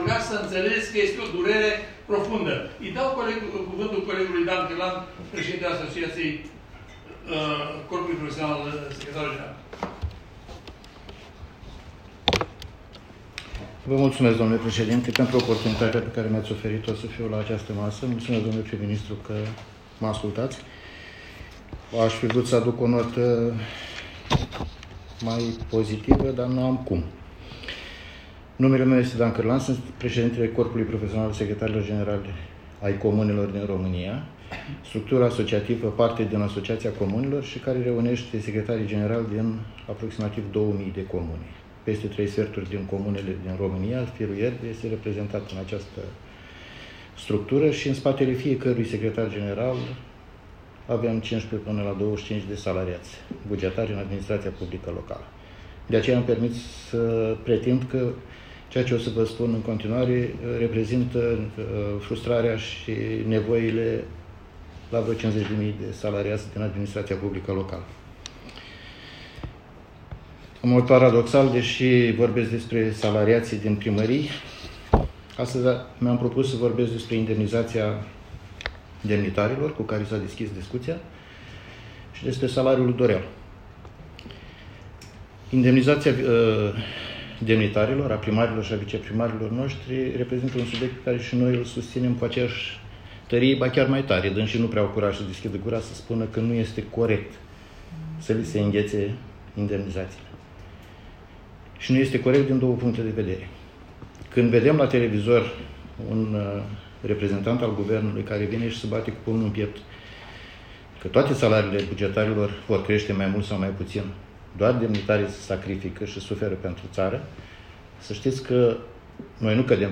Înregistrarea audio dezbateri
La data de 29 august 2022, la sediul Guvernului, a avut loc întâlnirea Prim-Ministrului României, domnul Nicolae-Ionel CIUCĂ, cu delegația Asociației Comunelor din România – ACoR.